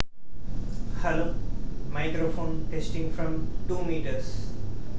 正如我前面提到的那样，人的声音是作为一种投入而发出的。
我将在此共享捕获的文件，配置为采样频率为16k，MCLK为4.096M，经过不同范围(米)的测试。
据分析，如果输入信号(人声)超过0.5米，我们不会收到任何溢出中断。